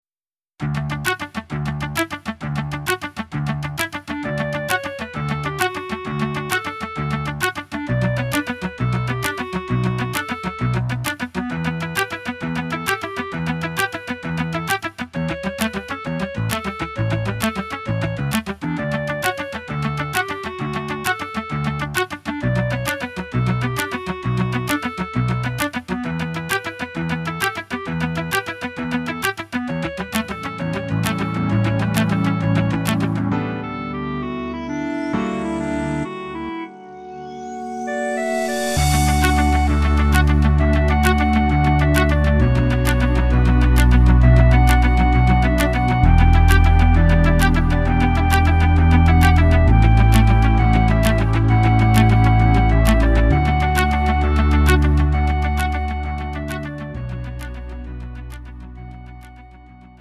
음정 원키 3:01
장르 가요 구분